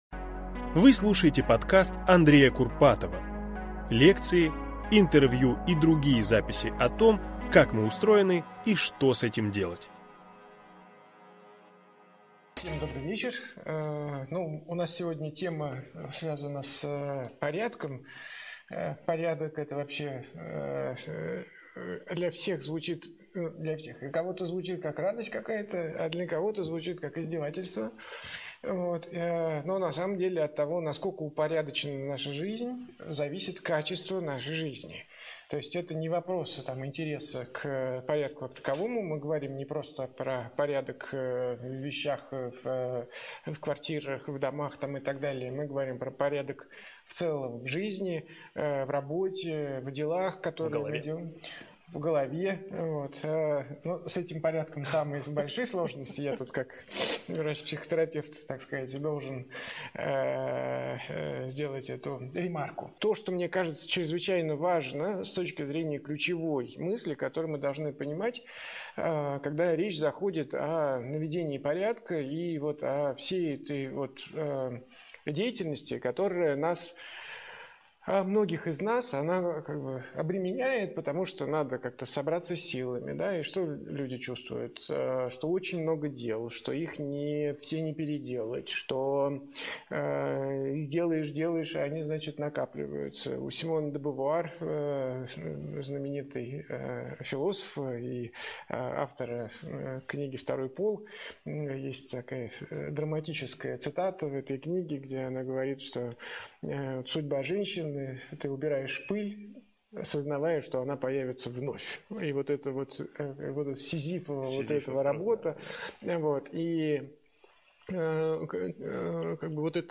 Аудиокнига Как навести порядок в делах, в доме и в жизни | Библиотека аудиокниг